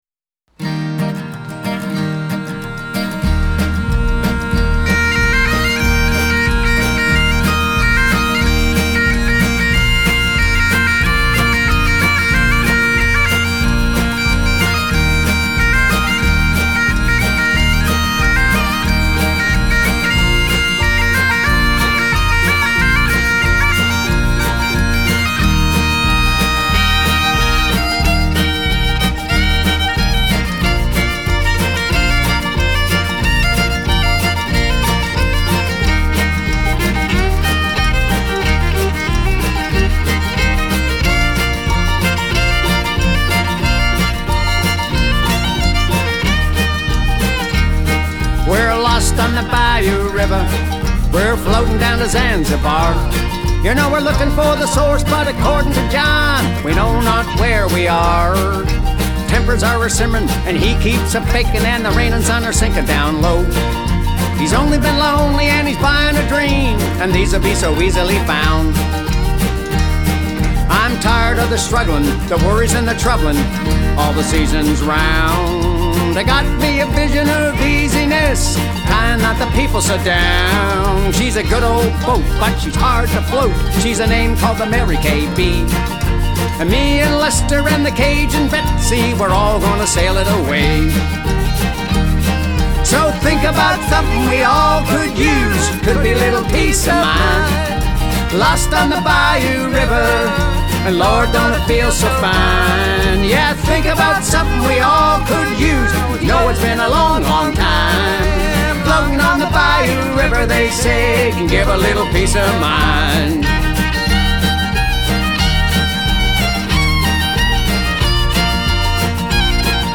This Cajun tune for pipes and guitar
Highland pipes & vocals
harmony vocals
fiddle
guitar
bass
Mandolin
percussion